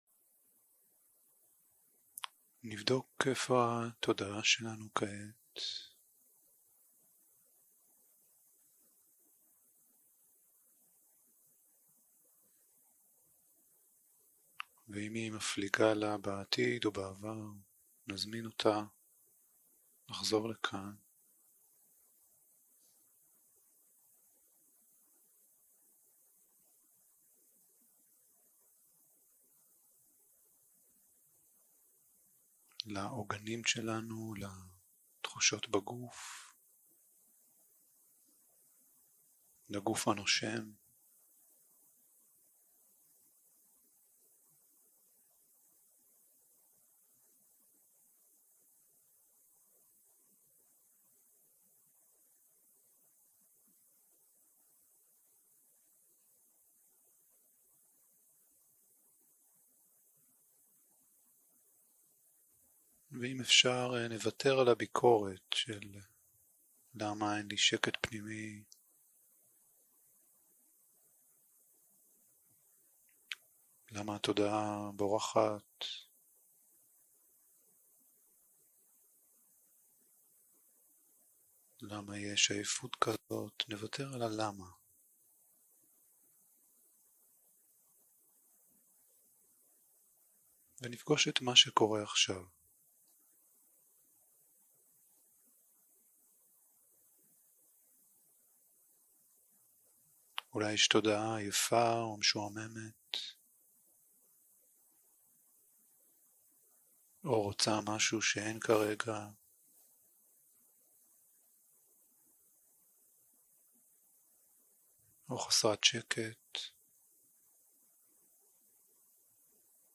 יום 2 - הקלטה 4 - צהרים - מדיטציה מונחית
Dharma type: Guided meditation